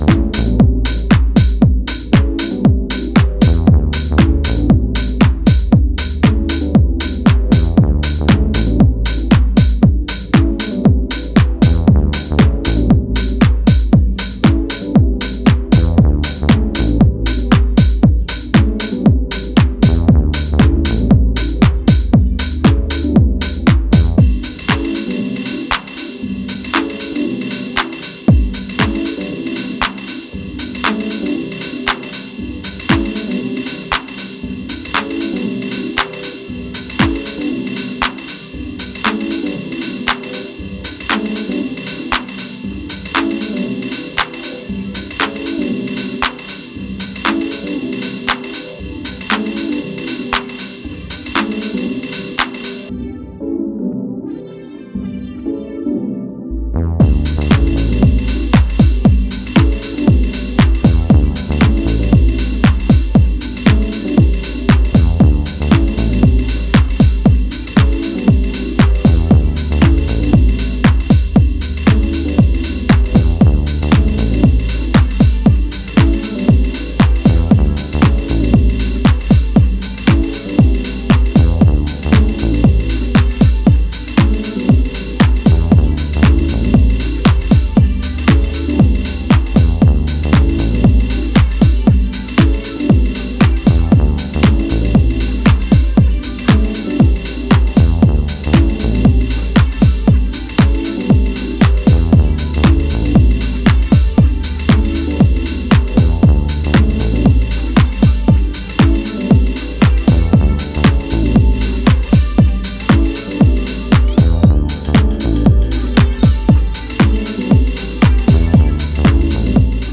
ウォームなディープ・ハウス集です！